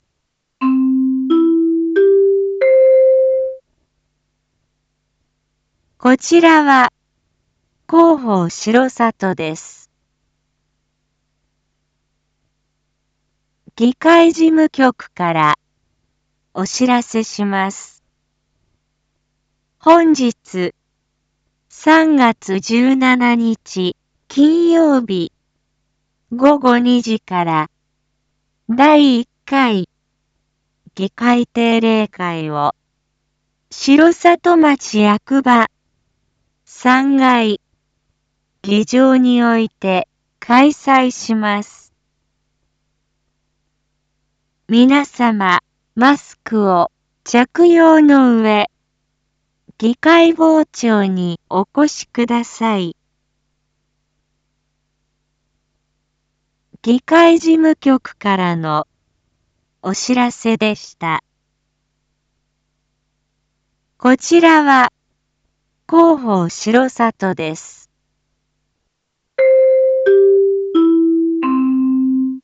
一般放送情報
Back Home 一般放送情報 音声放送 再生 一般放送情報 登録日時：2023-03-17 07:01:14 タイトル：R5.3.17 7時放送分 インフォメーション：こちらは広報しろさとです。